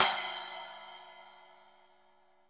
10SPLASH.wav